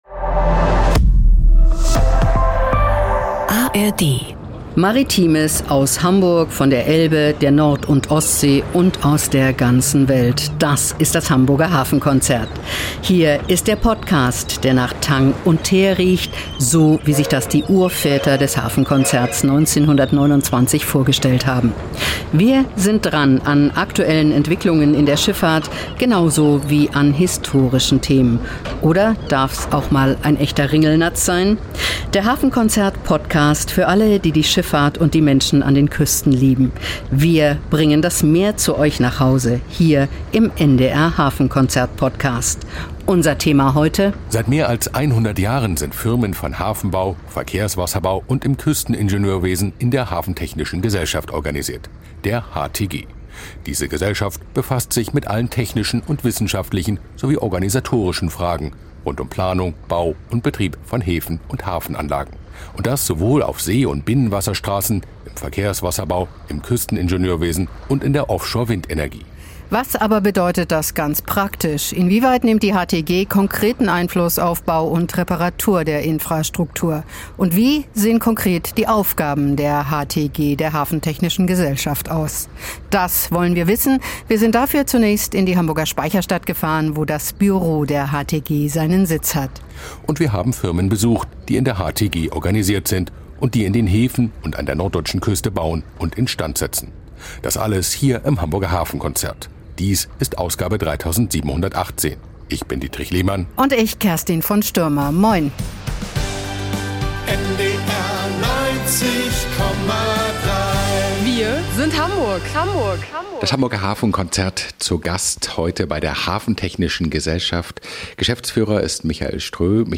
Der Hafenkonzert-Podcast ist in der Hamburger Speicherstadt, in Bremen und an der Schlei unterwegs.